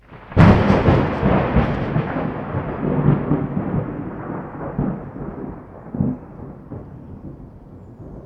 thunder-7.ogg